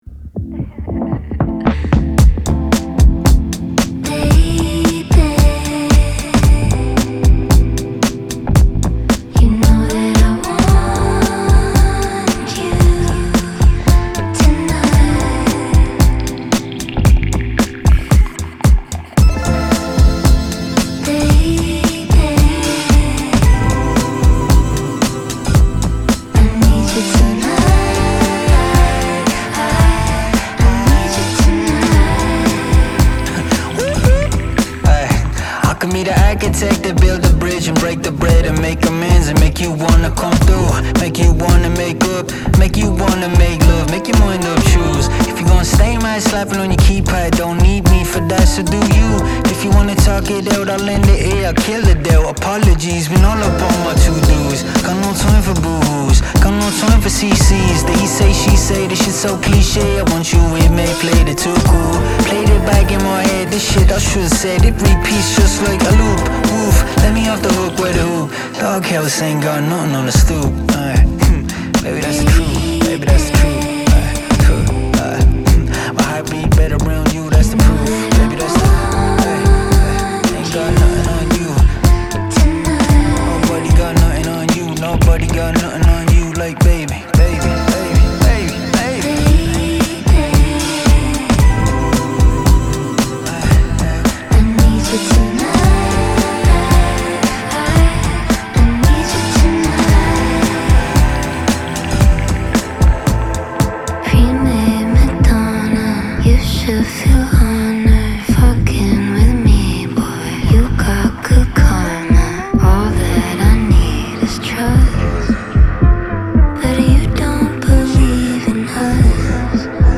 Hip Hop
features soothing atmospheres and tunes